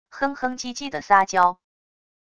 哼哼唧唧的撒娇wav音频